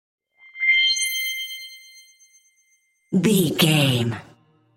Sound Effects
Atonal
funny
magical
mystical